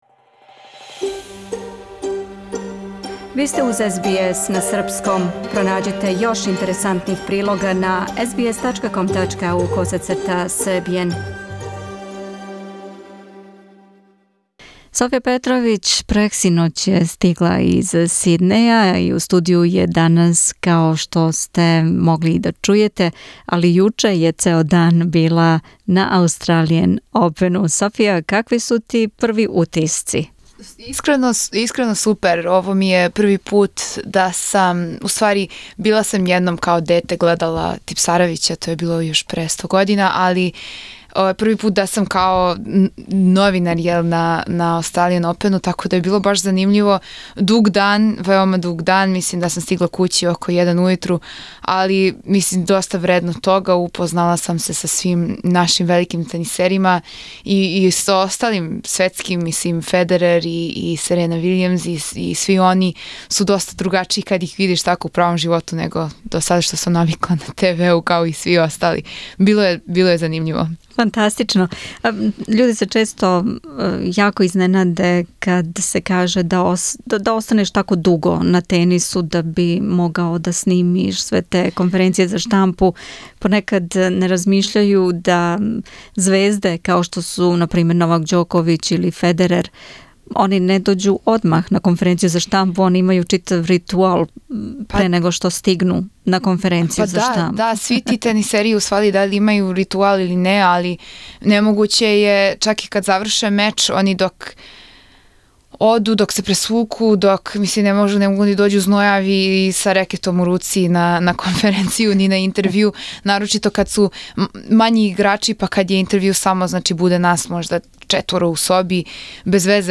Интервју са Ђоковићем, Лајовићем, Крајиновићем и са Нином Стојановић.